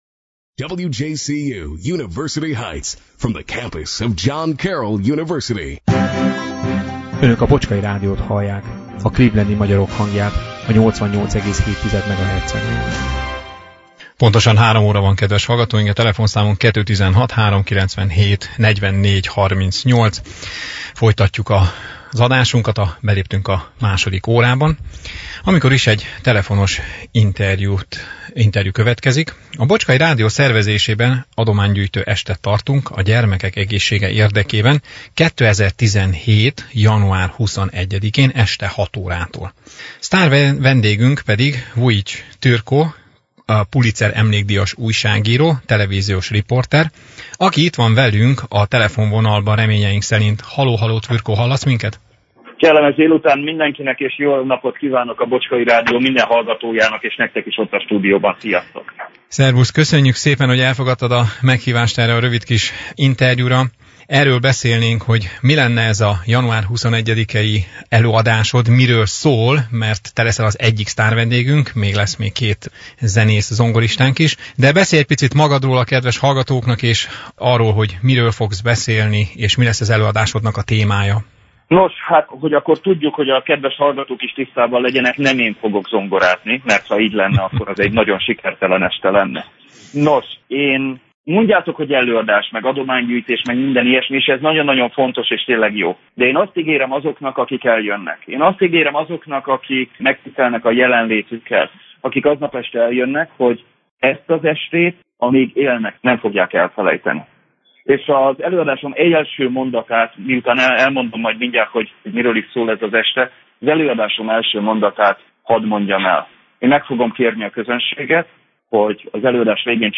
A vendég előadók egyike  Vujity Tvrtko, magyarországi sztárriporter akivel a múlt vasárnapi adásunkban, december 18-án sikerült egy élő interjú során beszélgetni.